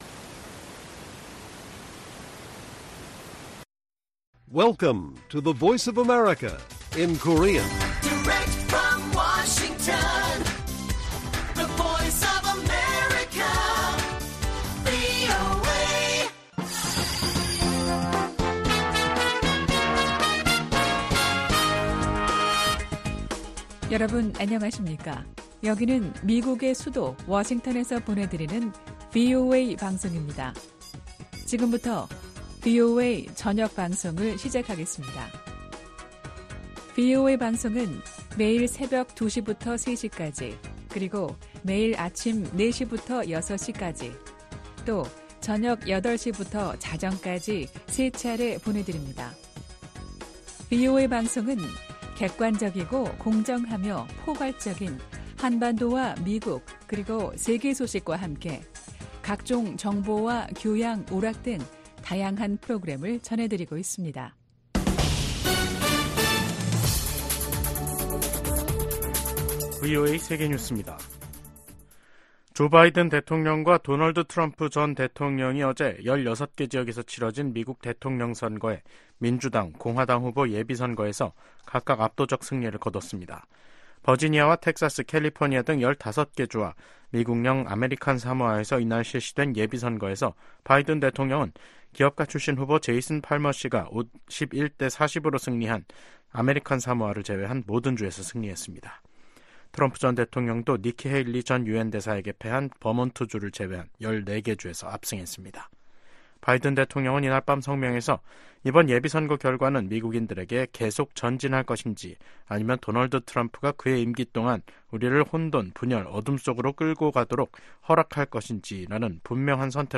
VOA 한국어 간판 뉴스 프로그램 '뉴스 투데이', 2024년 3월 6일 1부 방송입니다. 미 국무부 고위 관리가 북한 비핵화에 중간 단계 조치 필요성을 인정했습니다.